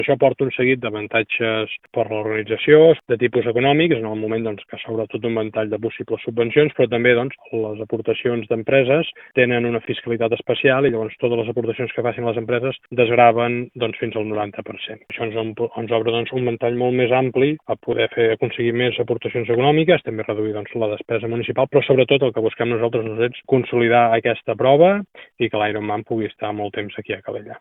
El Govern espanyol ha declarat l’IRONMAN Calella-Barcelona d’esdeveniment d’excepcional interès públic, com ho són també el Gran Premi de MotoGP i el Mobile. Li reconeix la rellevància i obre la porta a les entitats i empreses que vulguin patrocinar la prova esportiva a beneficiar-se d’una fiscalitat avantatjosa, tal com explica l’alcalde Marc Buch, que ho qualifica de molt bona notícia: